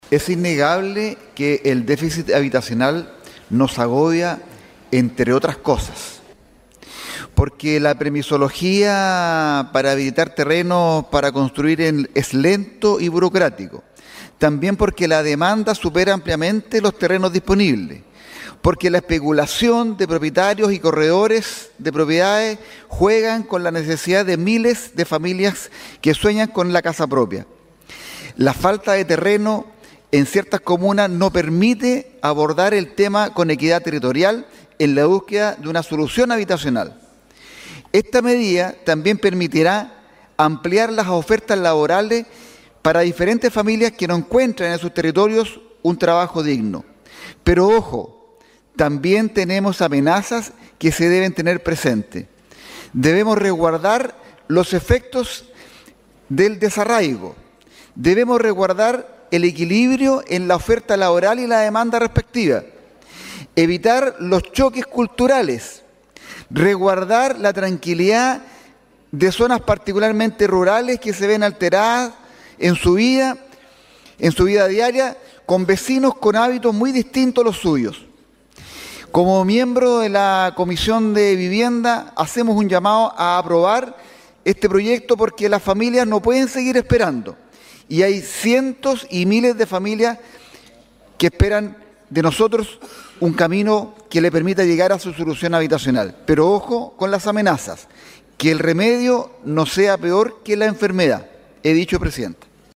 Durante su intervención en la Sala, el parlamentario enfatizó la urgencia de avanzar en esta materia.